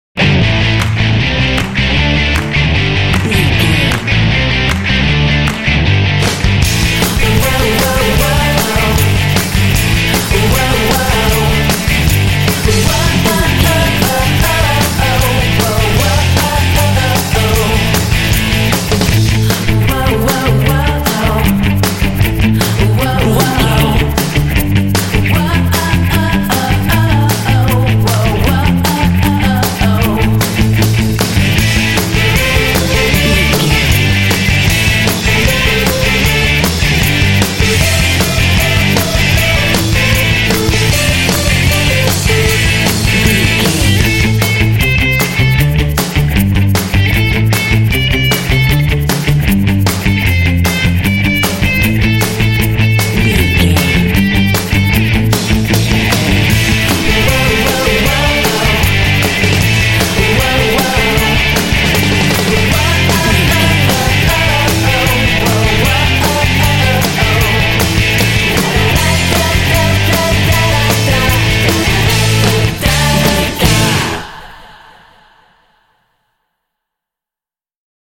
This Pop track is full of energy and drive.
Ionian/Major
driving
energetic
electric guitar
bass guitar
drums
electric piano
vocals
pop